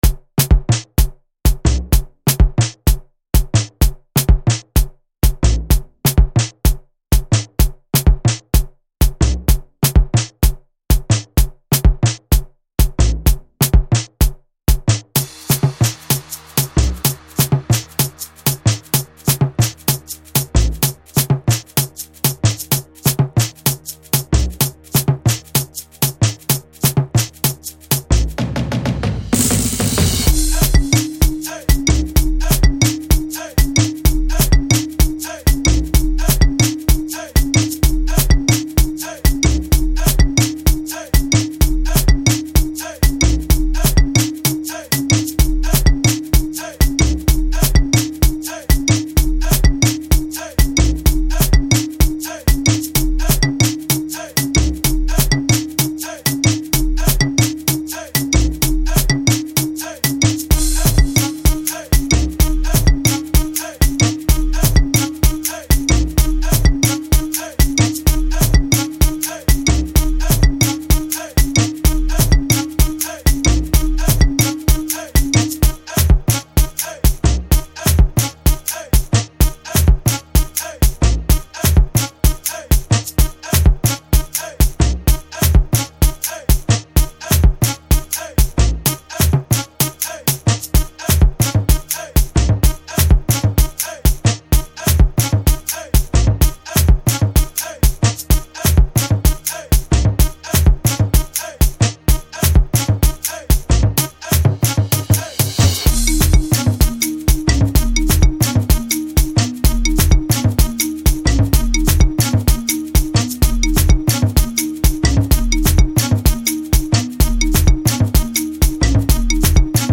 Afro House